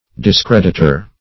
discreditor \dis*cred"it*or\